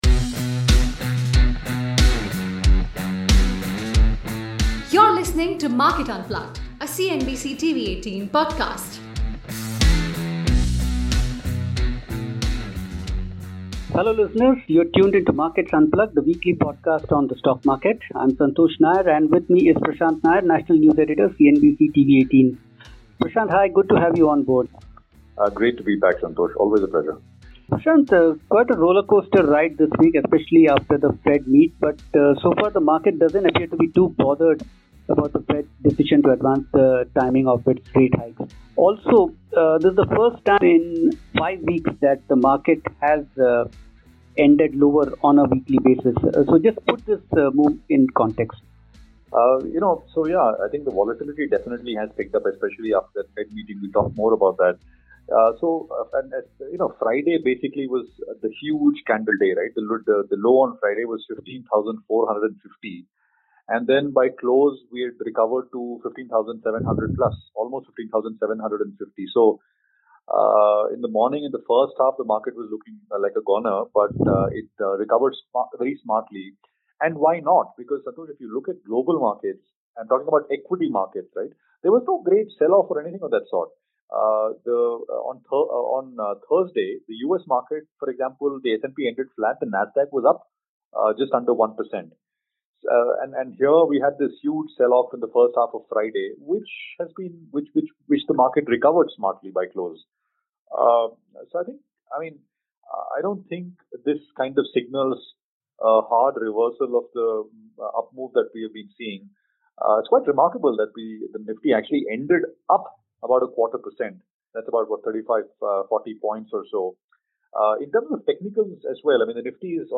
The duo discuss the following points: Why investors are unfazed by the Federal Reserve's decision to advance its time for hiking interest rates